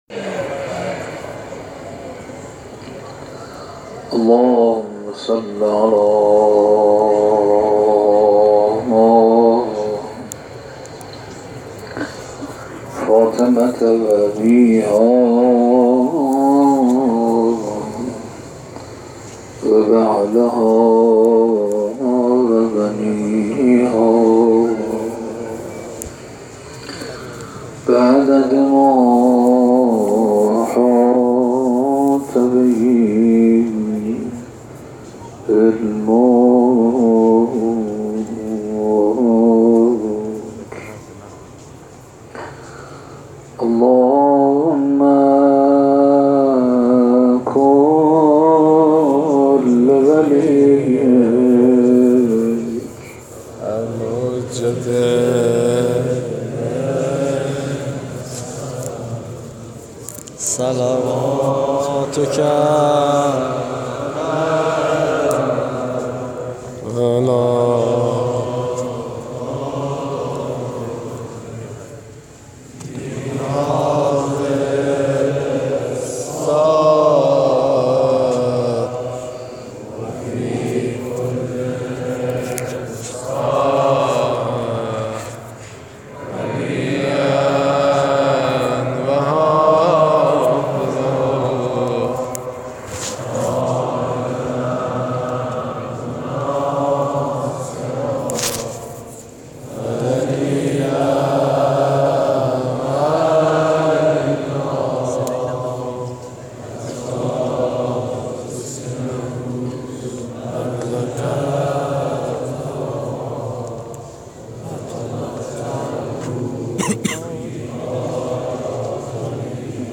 در سومین شب از عزاداری ماه محرم، مسجد ارک حال و هوایی متفاوت تر از قبل داشت.
صوت مراسم